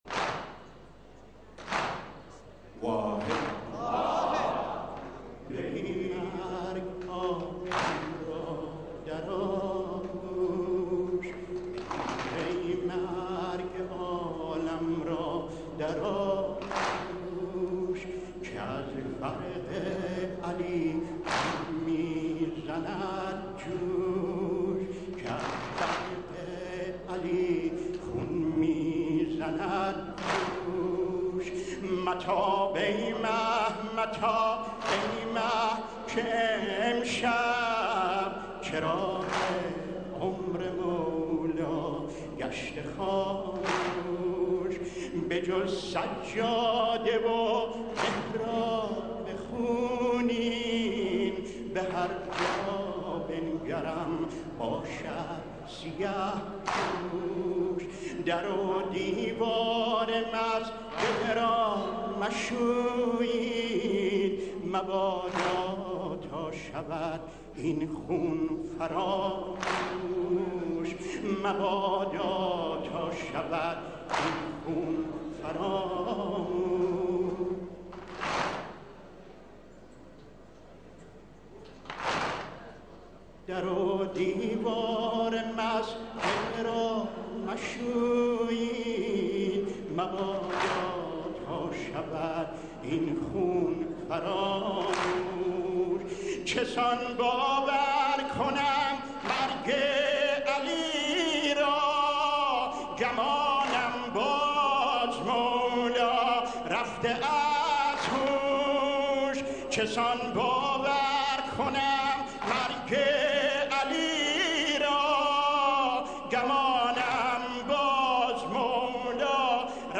برچسب ها: نوحه بوشهری ، دانلود